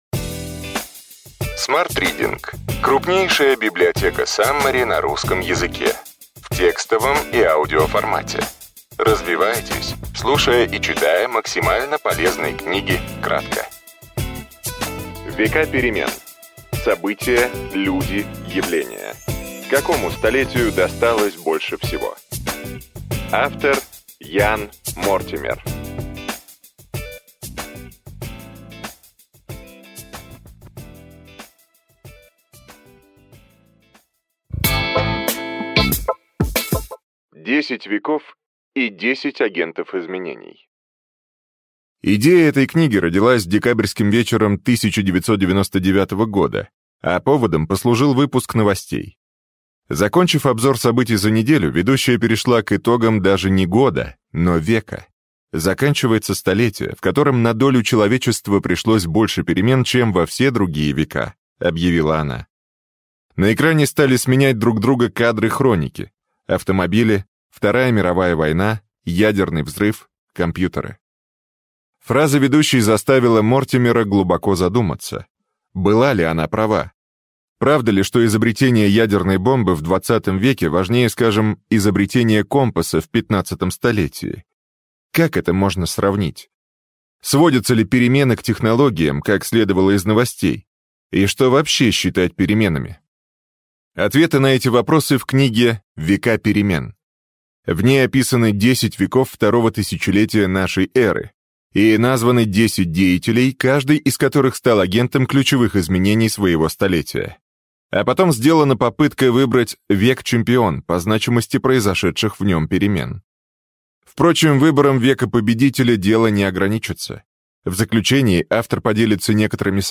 Аудиокнига Века перемен. События, люди, явления: какому столетию досталось больше всего?